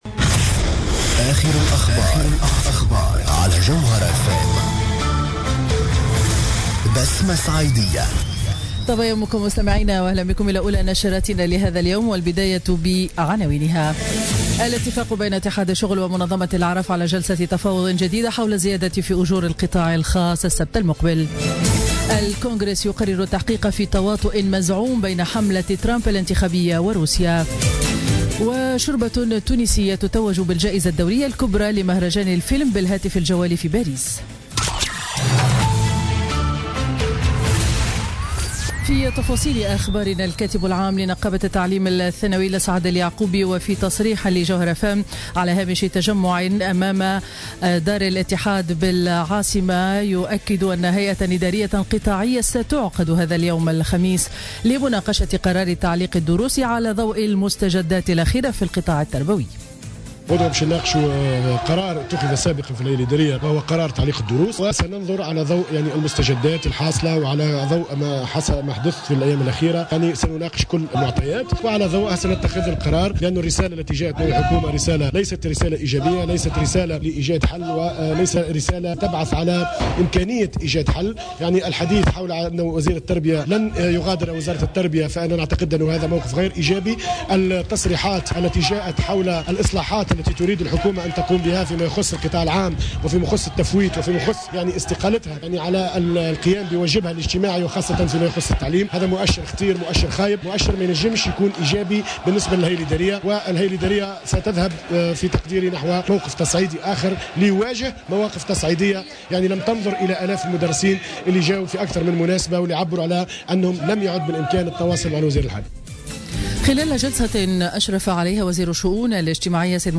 نشرة أخبار السابعة صباحا ليوم الخميس 2 مارس 2017